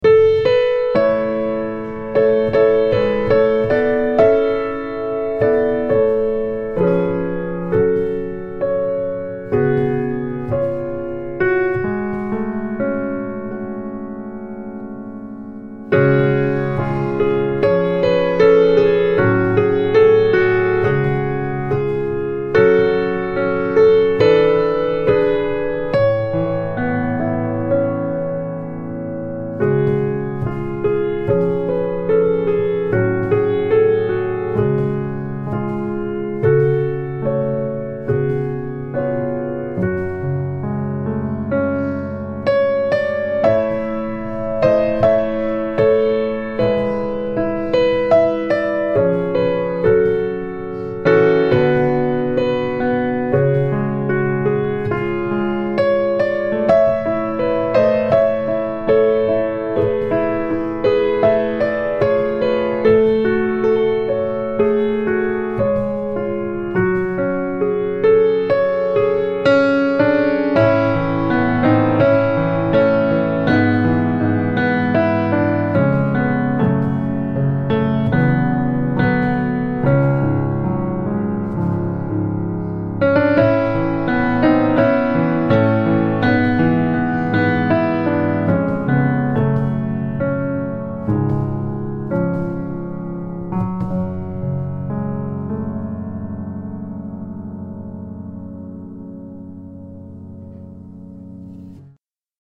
hymne nationnal ukrainien (piano
VERBITSKY (Mykhaylo), hymne nationnal ukrainien (piano) - ROTHKO, bleu jaune.mp3